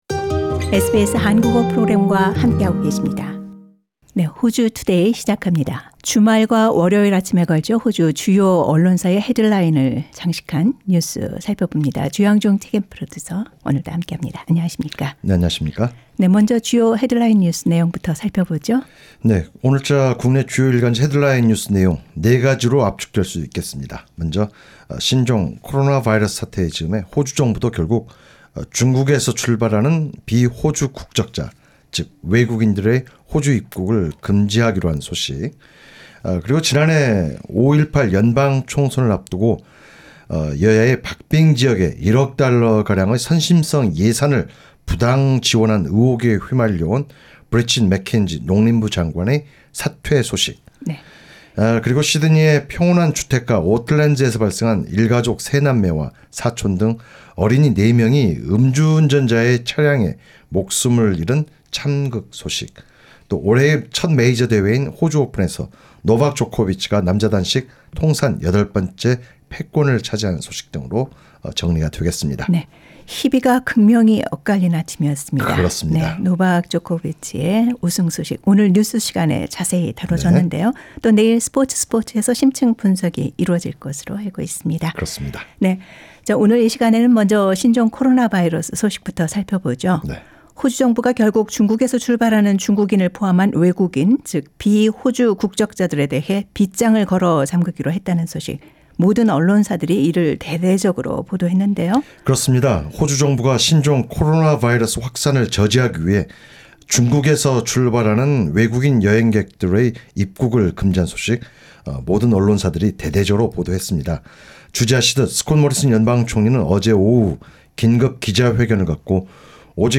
SBS 한국어 프로그램